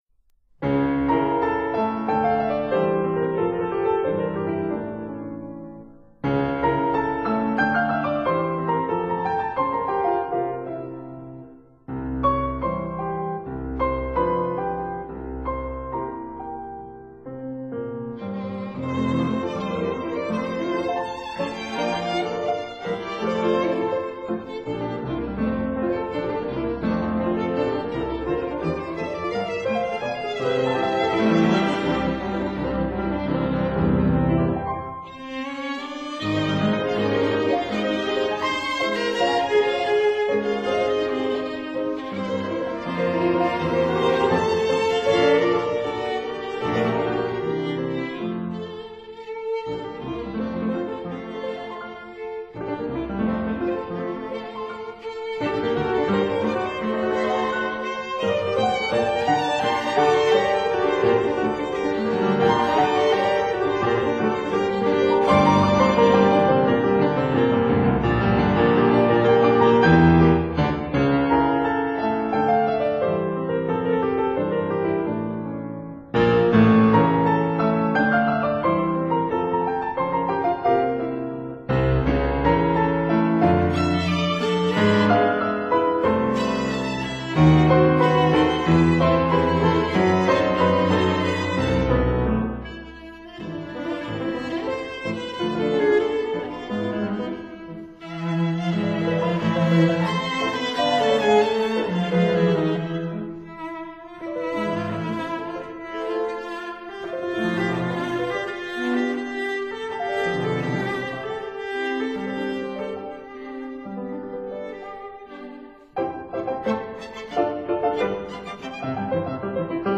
piano
violin
viola
cello